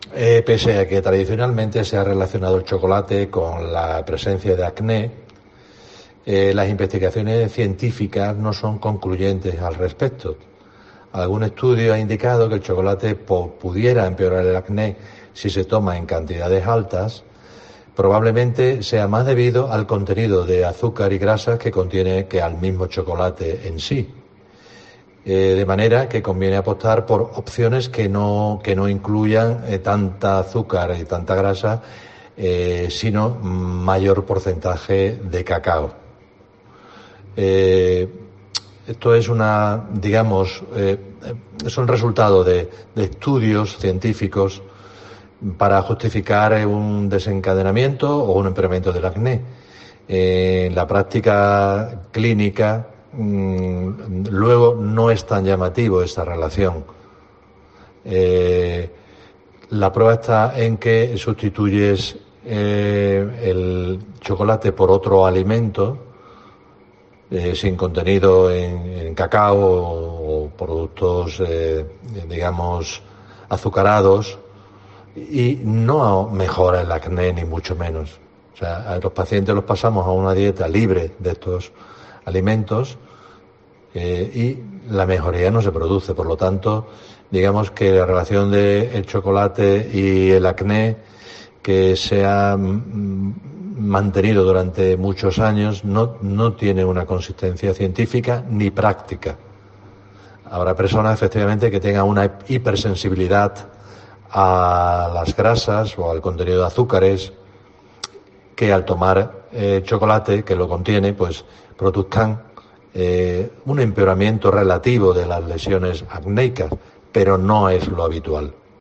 Un dermatólogo desvela en COPE la realidad de este mito